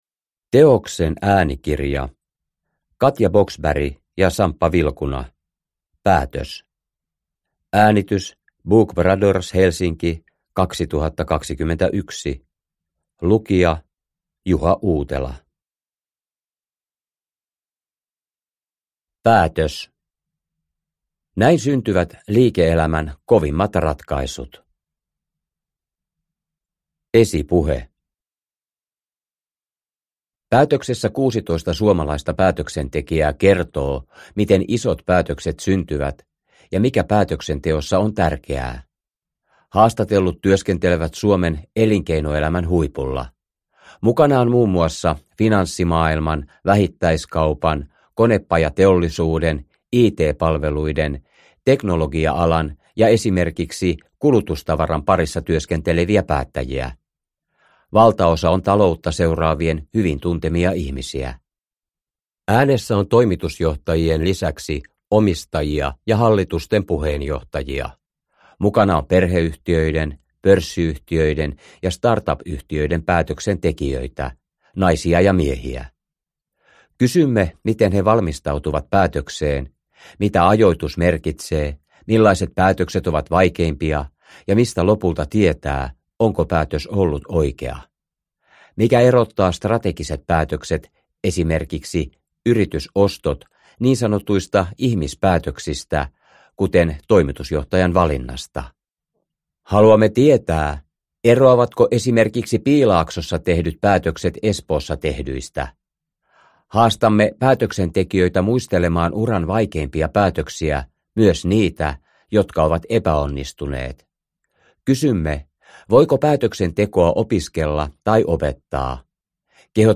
Päätös – Ljudbok – Laddas ner
Produkttyp: Digitala böcker